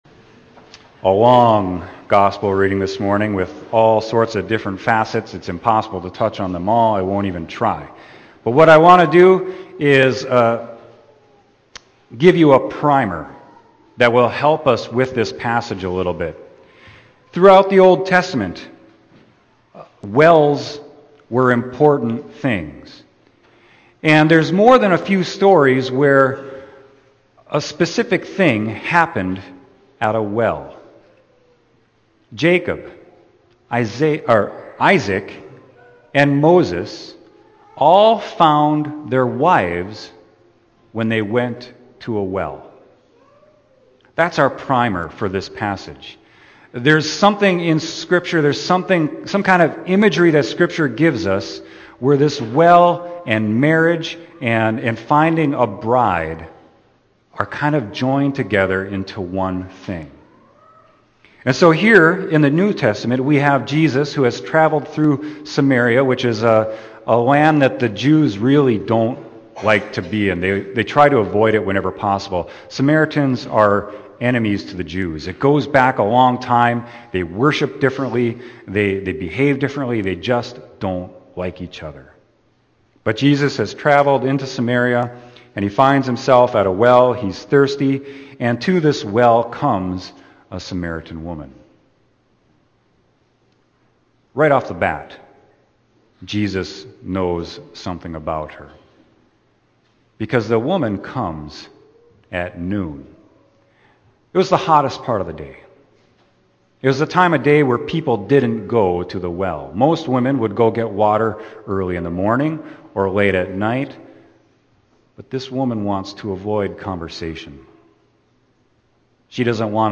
Sermon: John 4.5-42